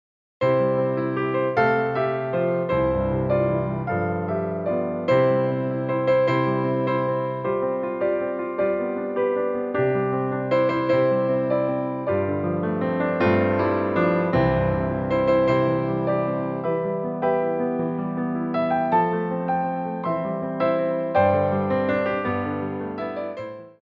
Tendus Combination
3/4 (8x8)